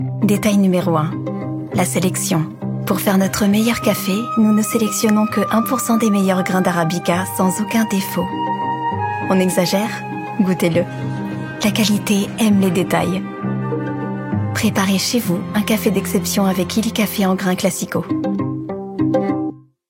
Elégante
Une comédienne voix off professionnelle pour vos spots publicitaires